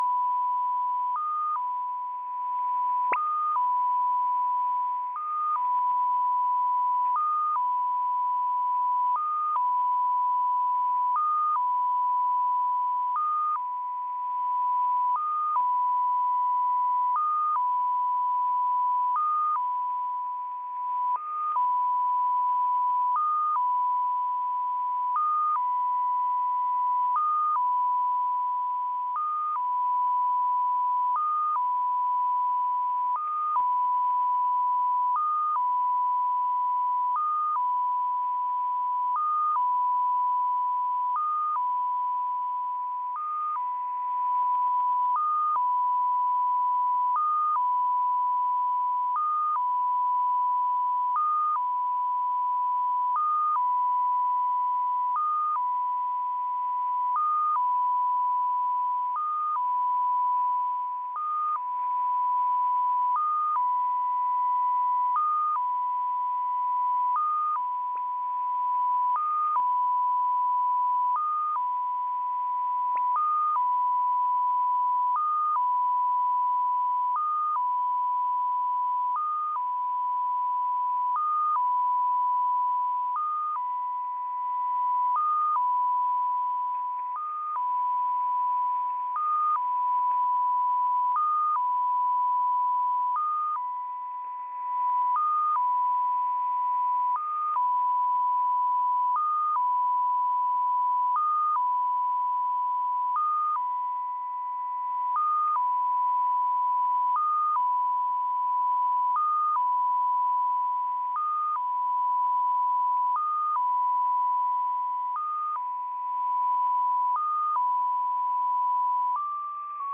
Number station
Intro tones to start, then at 2 minutes in the message tone starts.